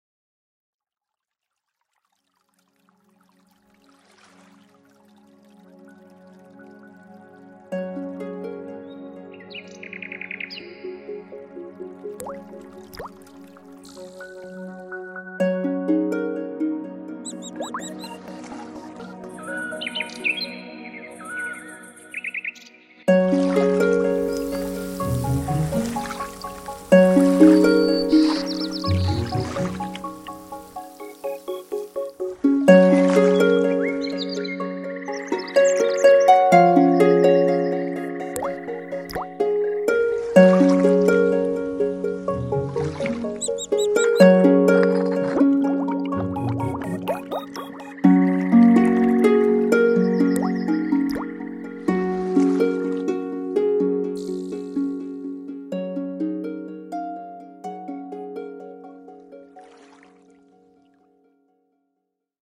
Beautiful Melody